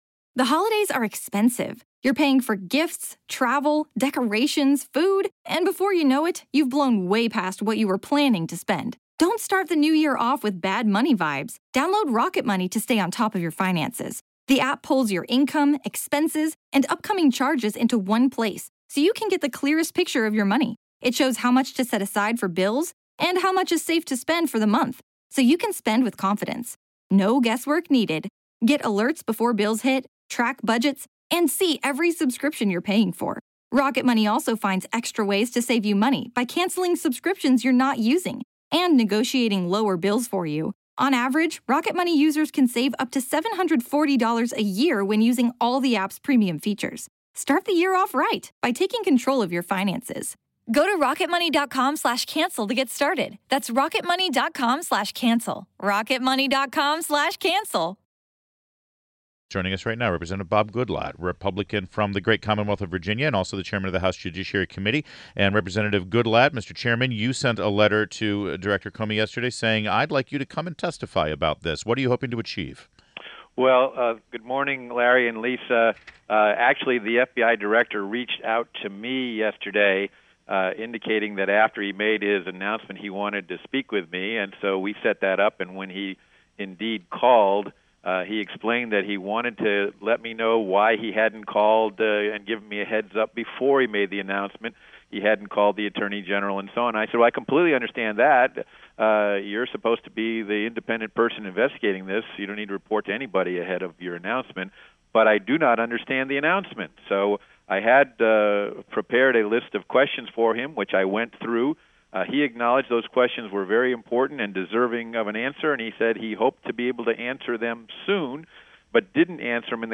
WMAL Interview Rep. Goodlatte 07.06.16